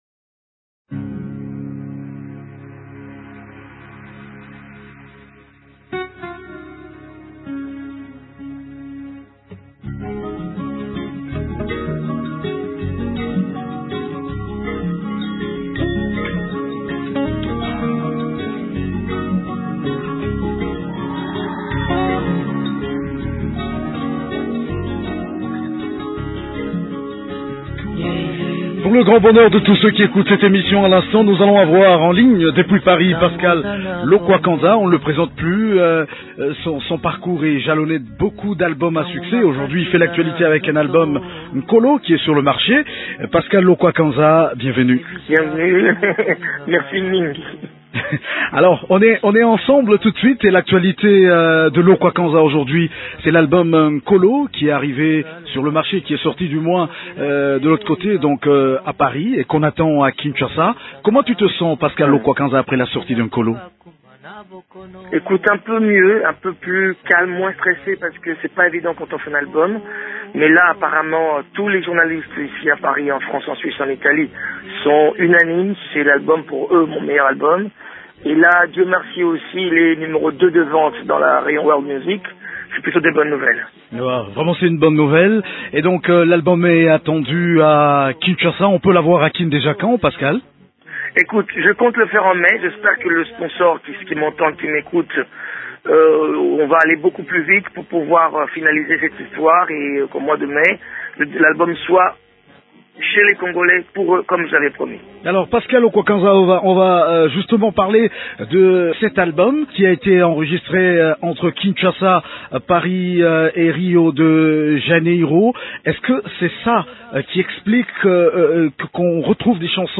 Lokua Kanza, artiste musicien congolais